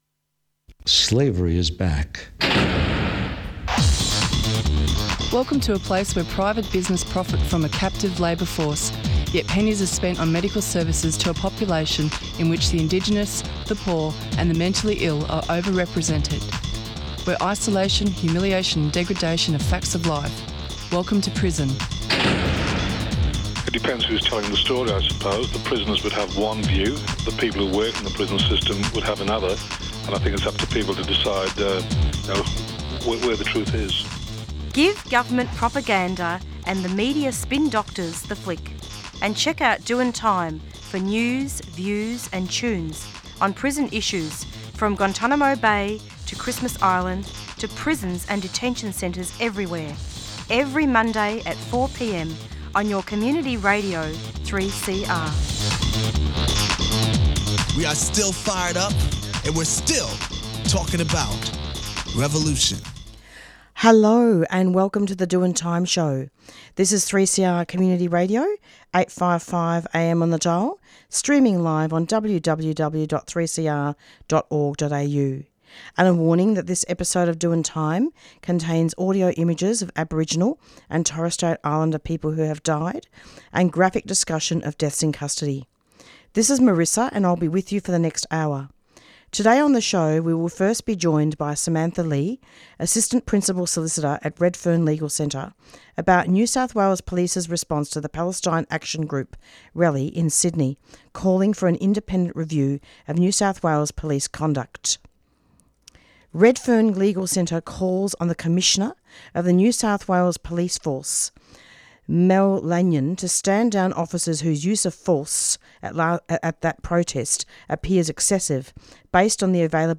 Content warning: This Episode of Doin' Time contains audio images of Aboriginal and Torres Strait Islander people who have died, and graphic discussion of deaths in custody.